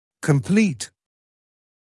[kəm’pliːt][кэм’плиːп]полный, завершённый, доскональный